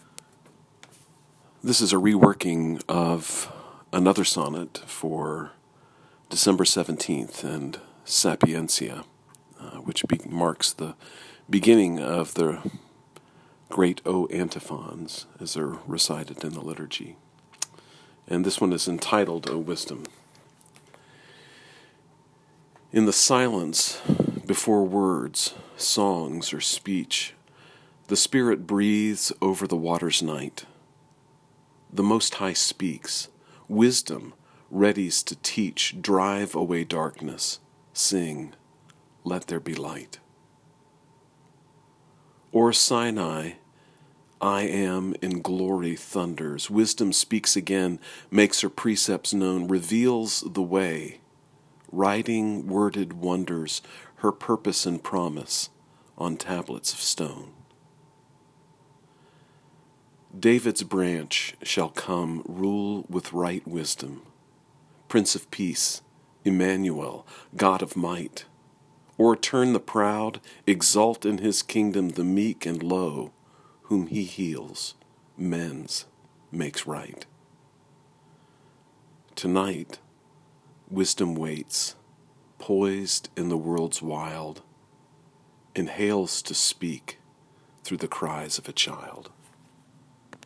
If it’s helpful you may listen to me read the sonnet via the player below.